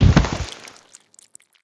pelt.wav